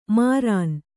♪ mārān